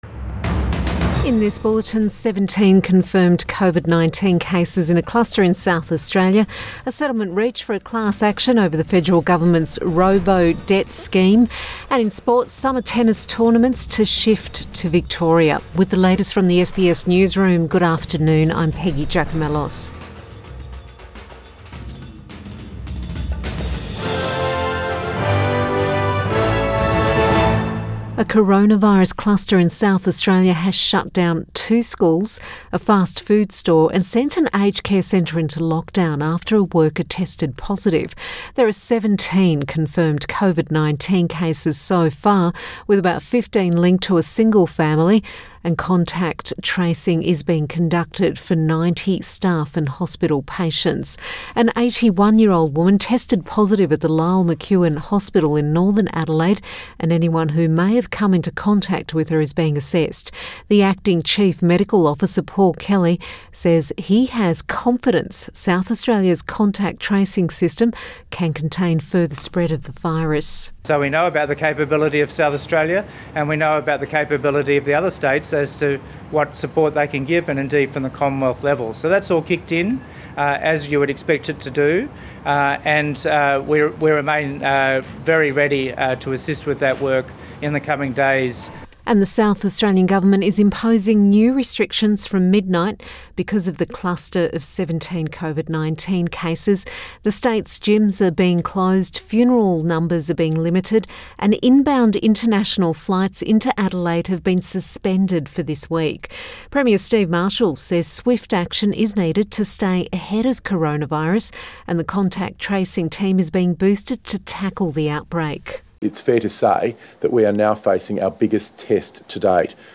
PM bulletin 16 November 2020